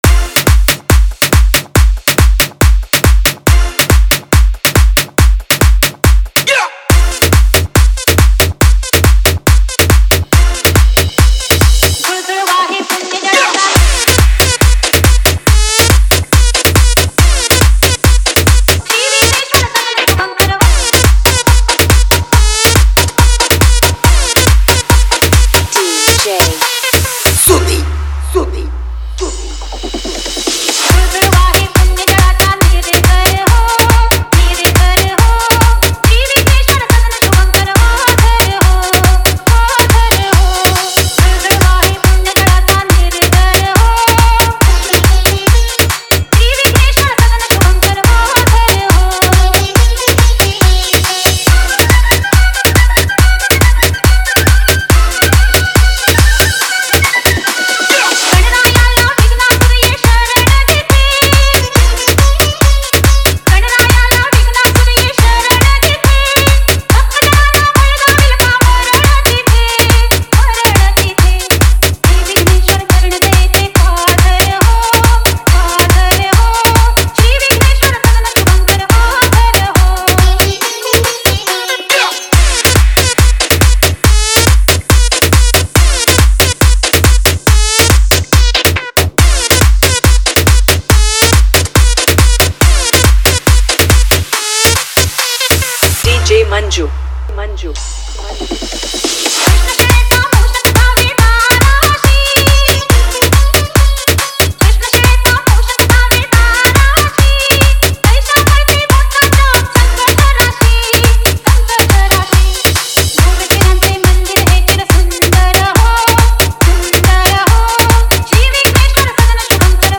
Ganesh chaturthi special EDM MIX Song